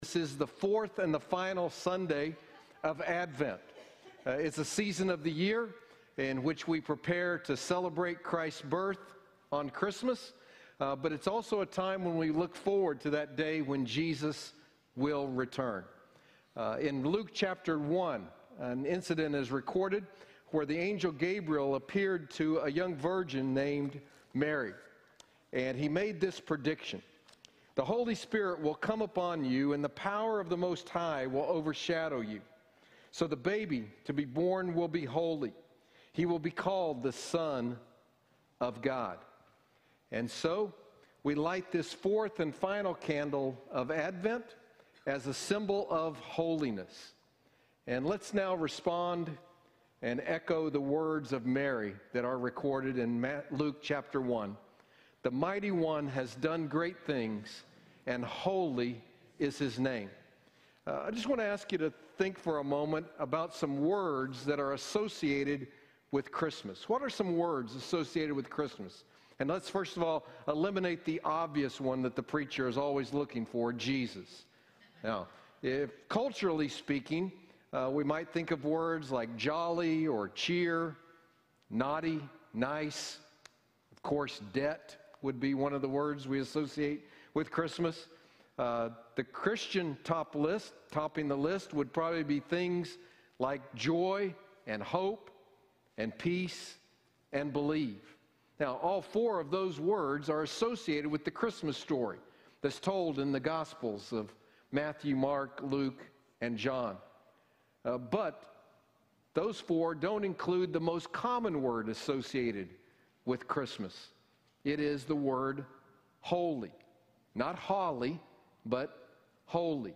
The Christian New Year Service Type: Sunday Morning Download Files Notes Bulletin « A New Year of Anticipation A New Year of Right(eous